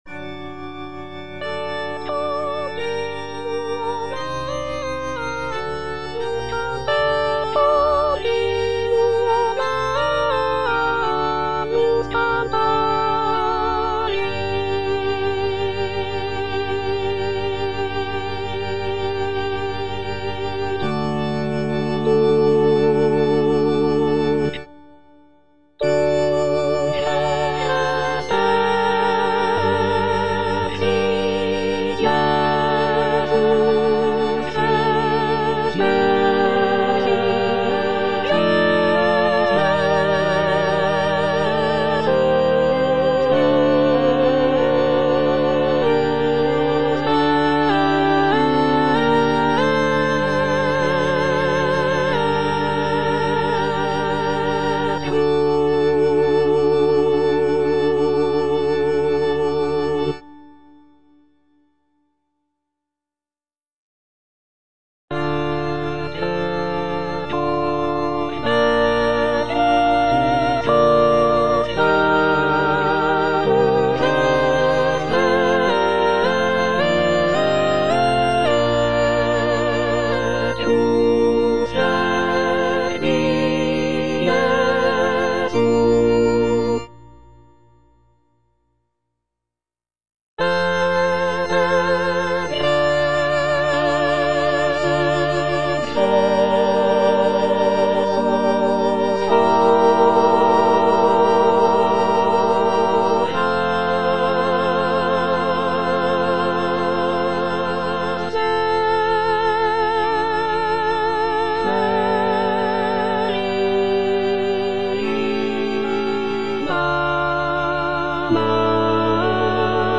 M.A. CHARPENTIER - LE RENIEMENT DE ST. PIERRE Et continuo gallus cantavit (soprano I) (Emphasised voice and other voices) Ads stop: auto-stop
It is an oratorio based on the biblical story of Saint Peter's denial of Jesus Christ.